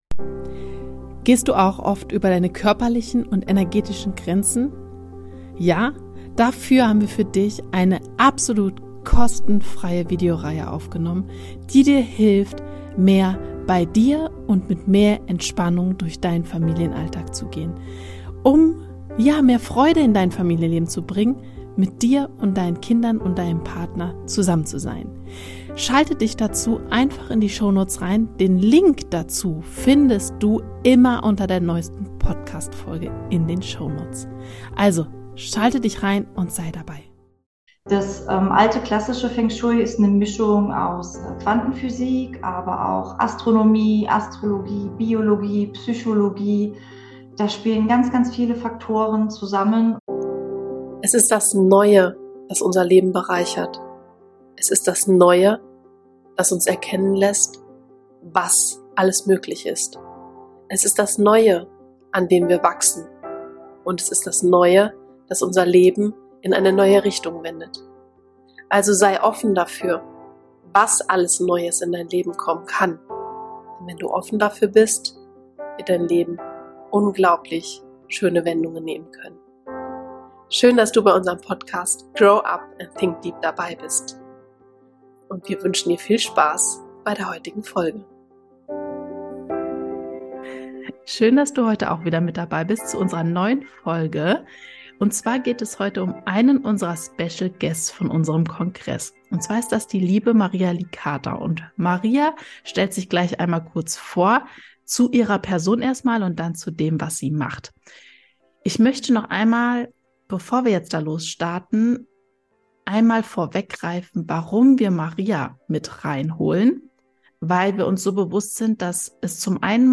Wie Feng Shui die Energie im Außen zum Fließen bringt. Interview